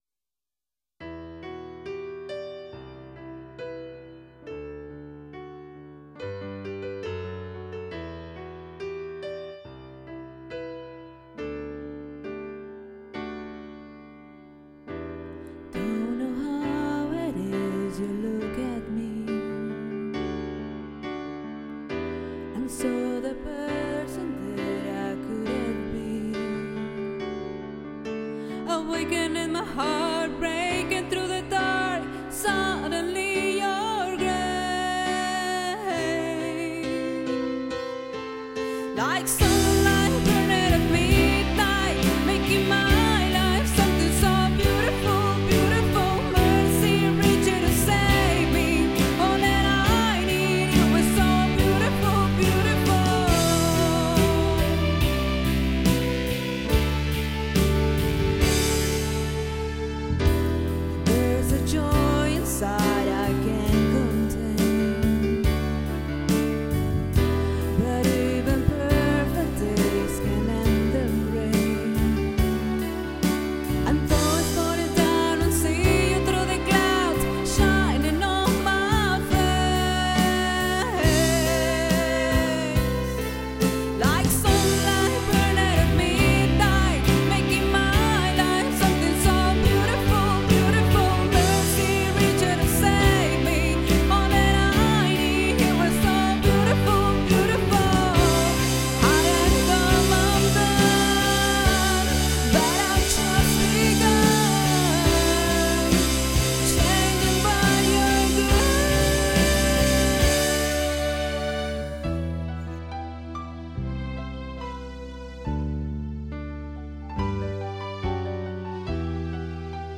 I recorded this version of "Beautiful,Beautiful" on my Tascam 2488 Neo.
I am aware of some plosives issues since I do not have a pop filter yet.
I recorded all tracks using my Yamaha MM8 .